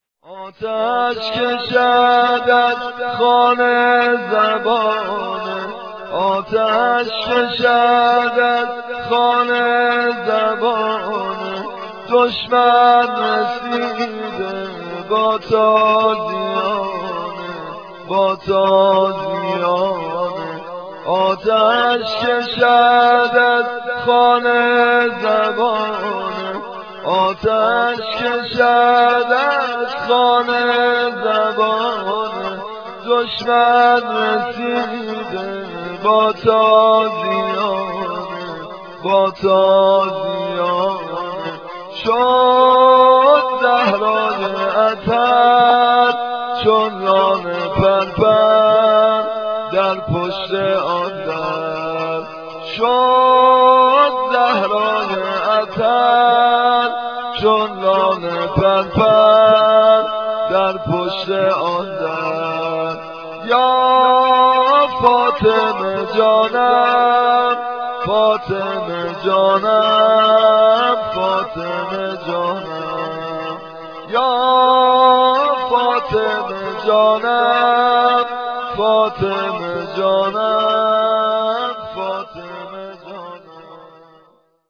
صوت / قابل توجه مداحان جوان / نوحه پیشنهادی + شعر
نوحه شهادت حضرت زهرا (س) سبک قدیمی عقیق